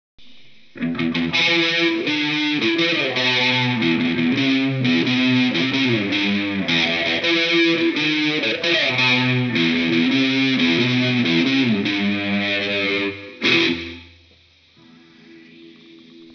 Rickenbacker style 6 string
now changed with  some Reflex active single coils.
Now fitted with 9v preamp and active Reflex pickups